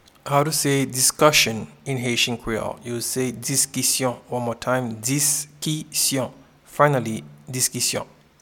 Pronunciation and Transcript:
Discussion-in-Haitian-Creole-Diskisyon.mp3